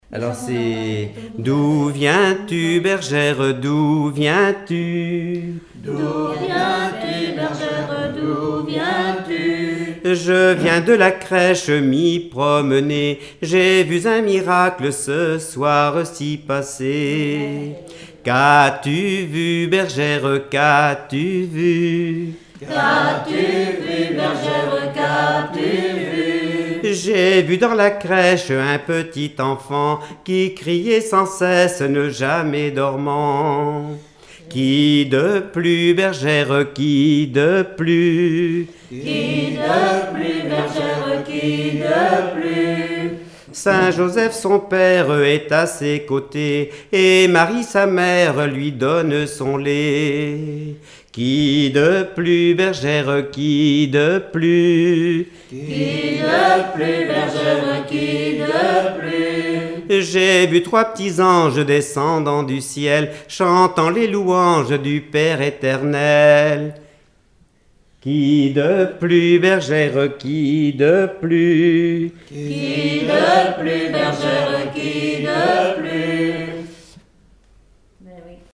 Chansons traditionnelles
Pièce musicale inédite